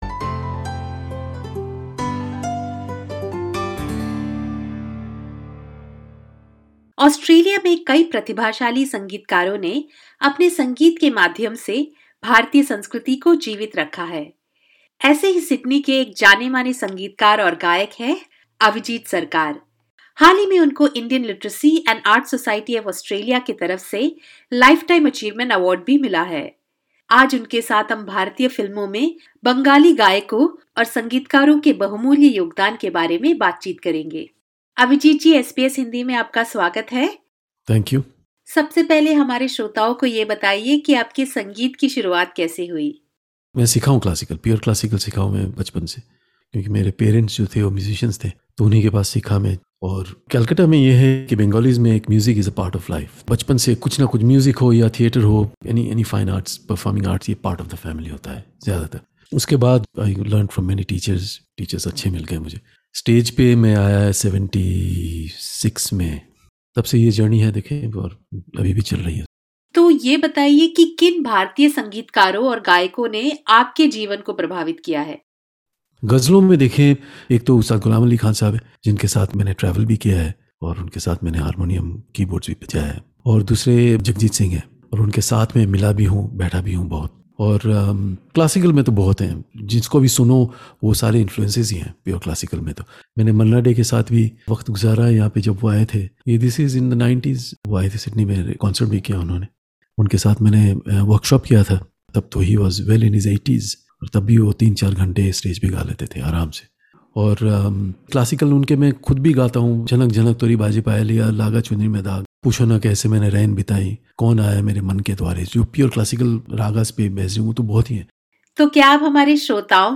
During an interview with SBS Hindi